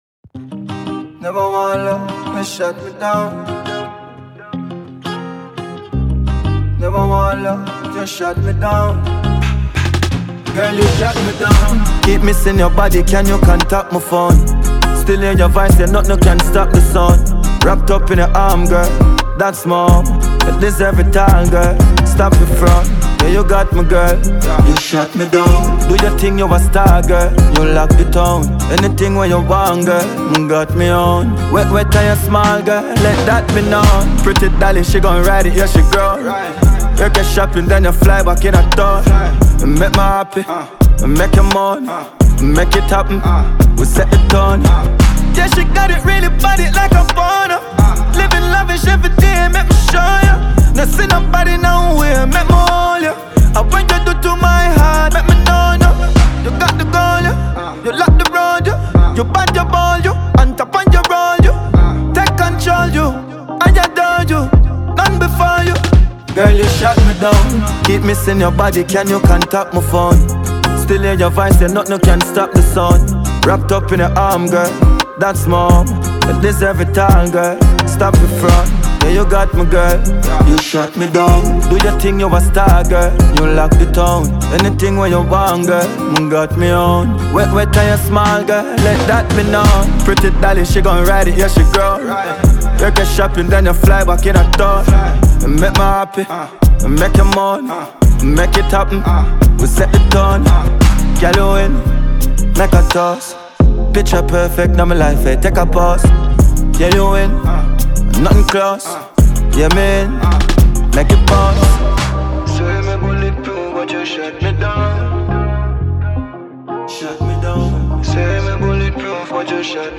Dancehall
a hard-hitting, rhythmic instrumental that demands attention
Genre: Dancehall / Street Dancehall